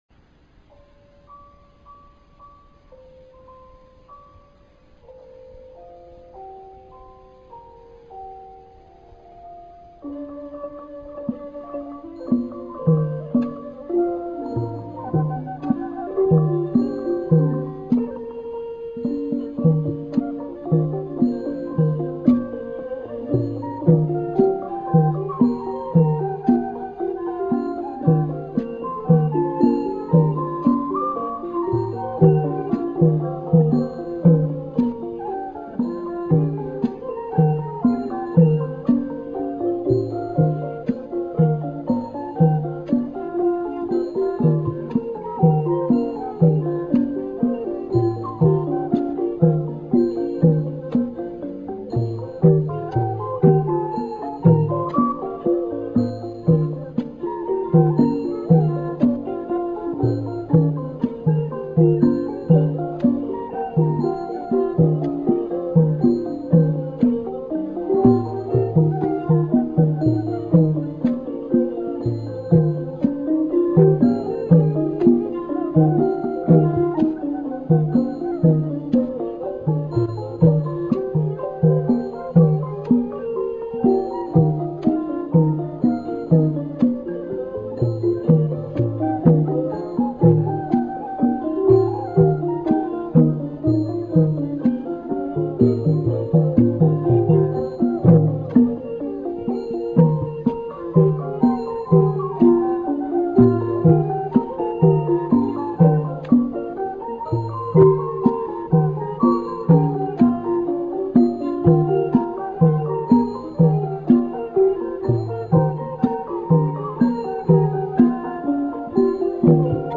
Piphat Duk dam baan
A set of 7 pitches Gongs
One Khlui-U
A pair of Glawng Khaek (Other rhythmic percussion sometimes added.)
The Piphat Doughdamban is always played indoor since soft-sounding instruments comprise  the ensemble
PiphatDuk.mp3